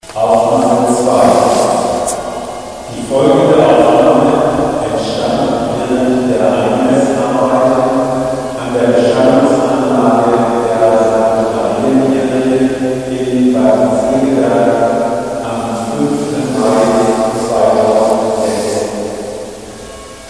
Hörbeispiel OHNE Induktion
(Mit dem folgenden Klangbeispiel von Hören ohne Barriere – HoB e.V. können Sie sich selbst überzeugen, welchen deutlichen Unterschied eine induktive Höranlage mit sich bringt.)
Hoerbeispiel_mit-Mikrofon-ueber-Lautsprecher.wav